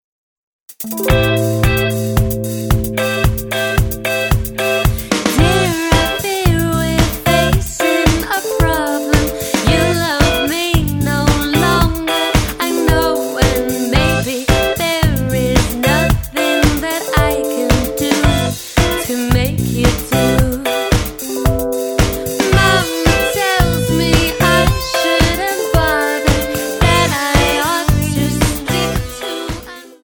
Tonart:Am Multifile (kein Sofortdownload.
Die besten Playbacks Instrumentals und Karaoke Versionen .